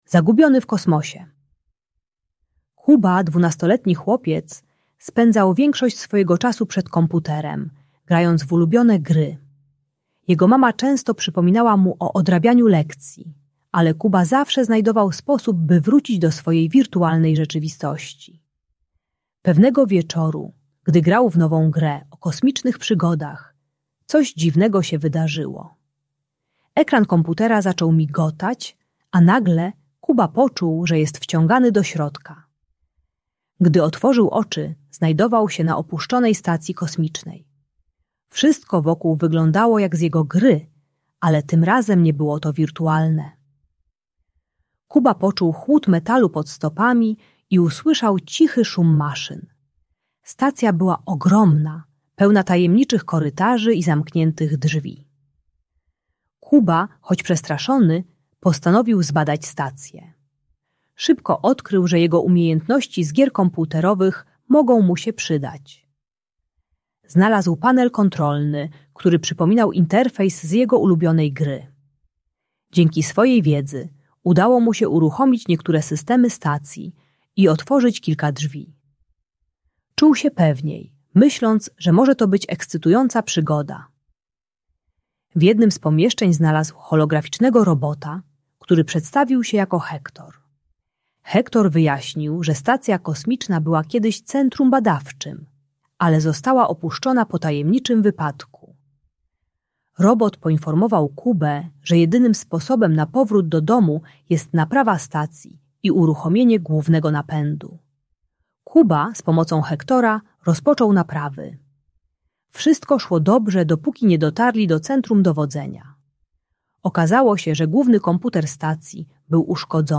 Zagubiony w Kosmosie - Bajki Elektronika | Audiobajka